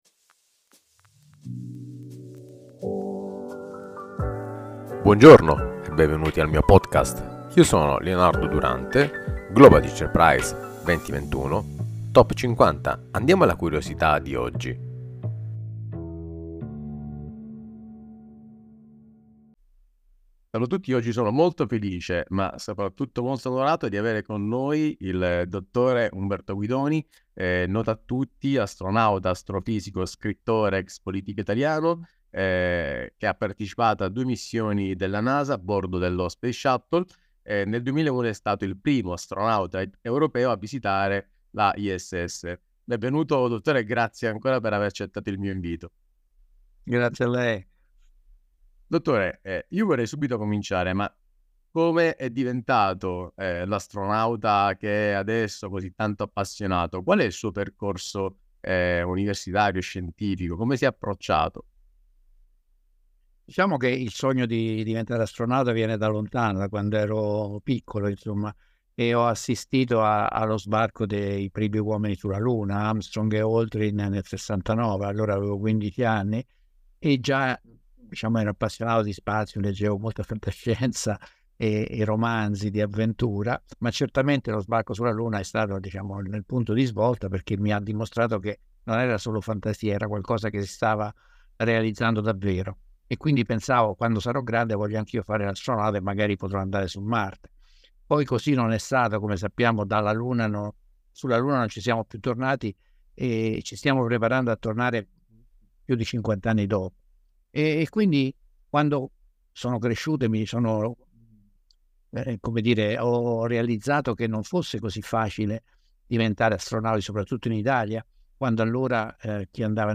Intervista al Dr. Umberto Guidoni astronauta italiano